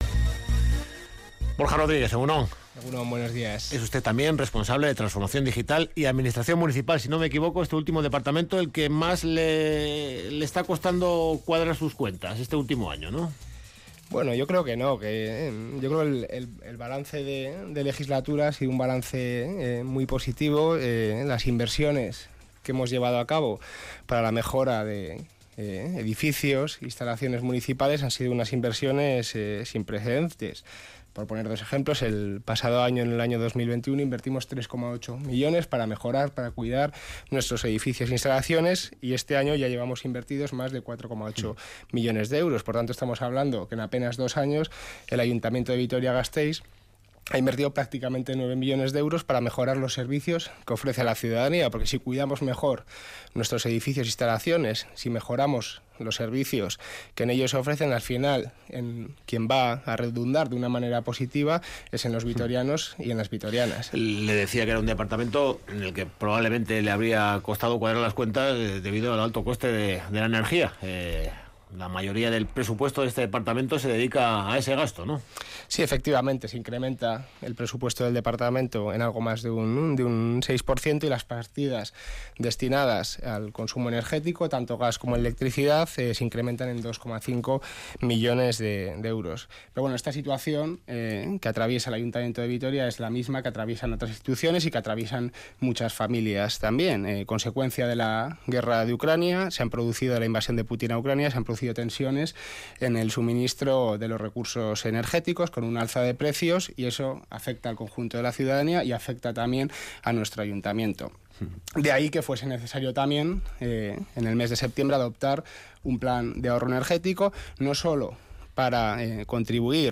Entrevistado en Radio Vitoria, el concejal de Administración Municipal, Borja Rodríguez, asegura que esa cifra se alcanzará con la bajada de un grado en la temperatura de las piscinas
ENTREVISTA-DEL-DIA